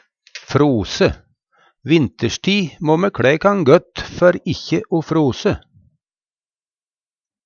frose - Numedalsmål (en-US)